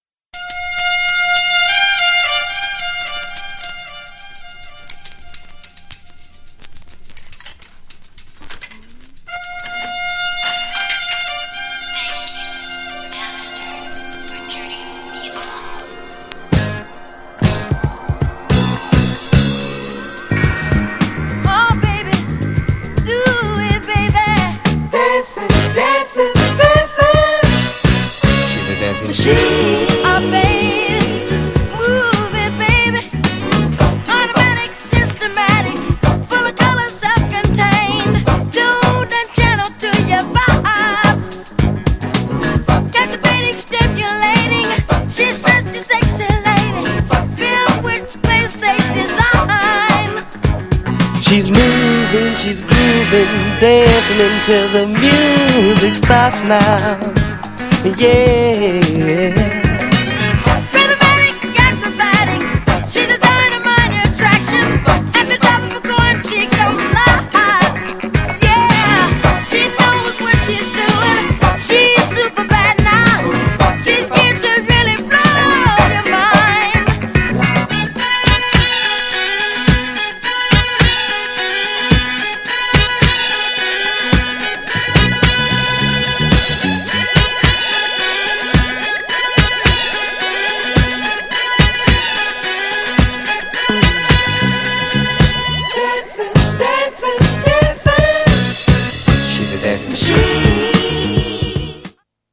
GENRE Dance Classic
BPM 86〜90BPM